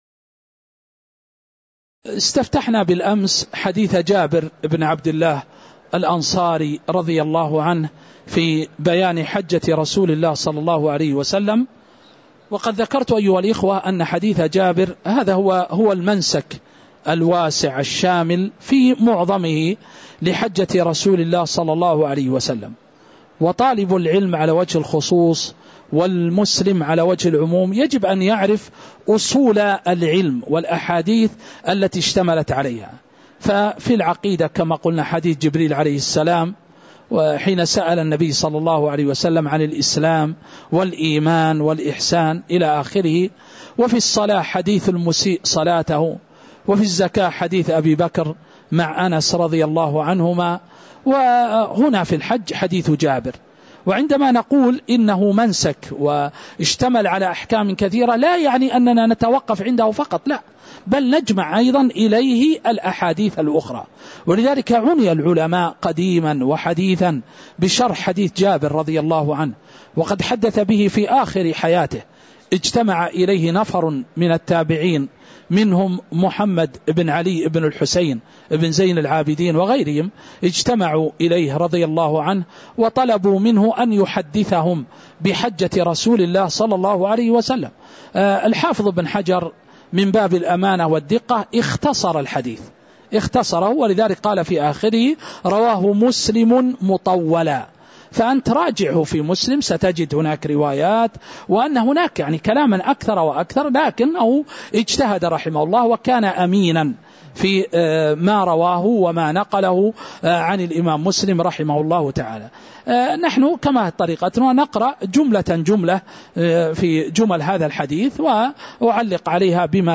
تاريخ النشر ١٨ ذو القعدة ١٤٤٥ هـ المكان: المسجد النبوي الشيخ